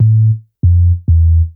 bass_05.wav